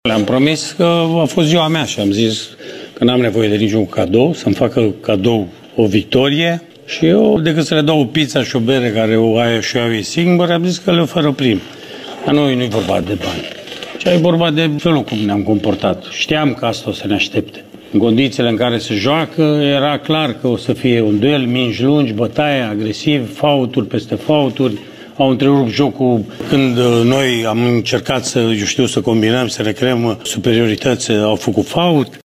Antrenorul Mircea Rednic a vorbit și despre cadoul cerut la aniversarea a 63 de ani, trei puncte în meciul cu Unirea Slobozia:
14-apr-8.30-Rednic-post-Unirea-Slobozia.mp3